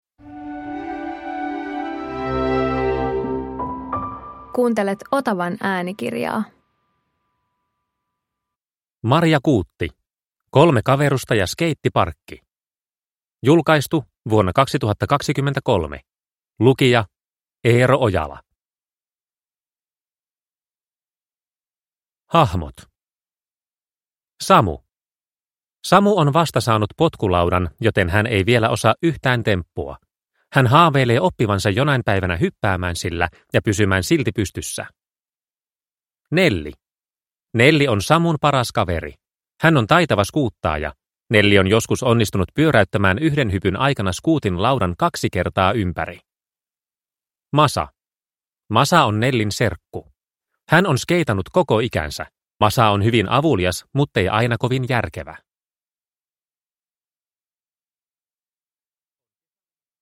Kolme kaverusta ja skeittiparkki – Ljudbok – Laddas ner